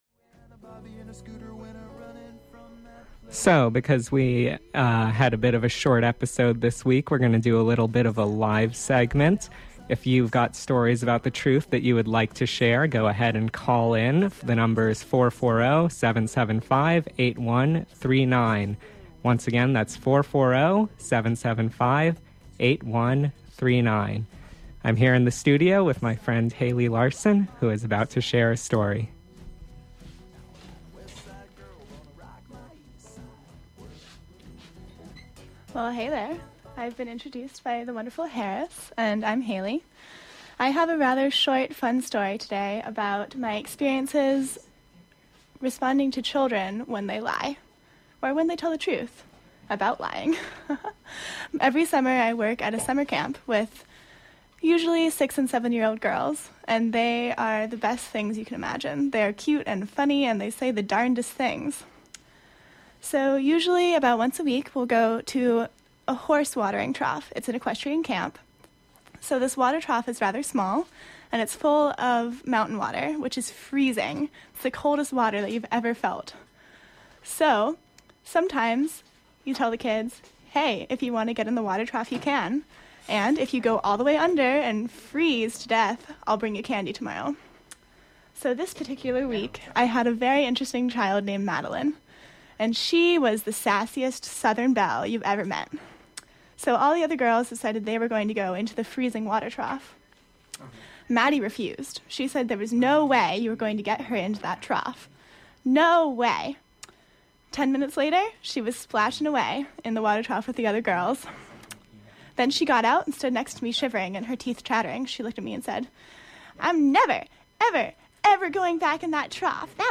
the_second_page_truth_live.mp3